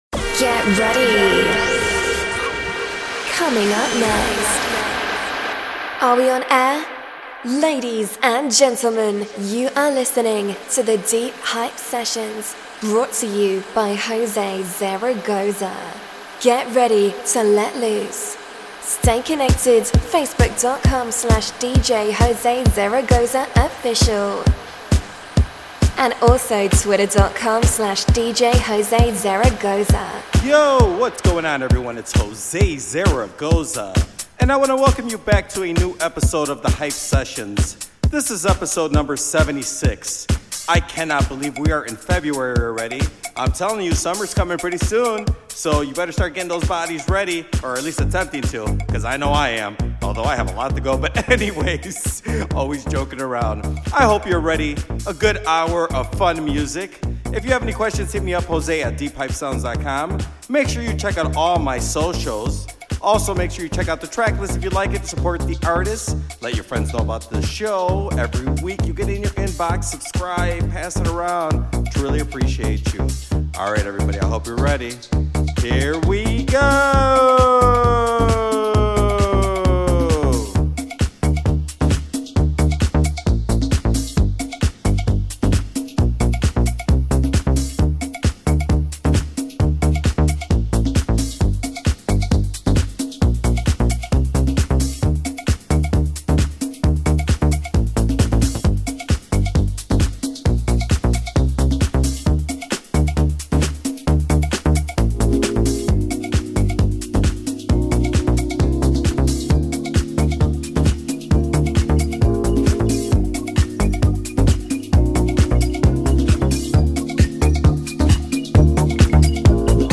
beats to keep you nice and warm. A fun show of great music.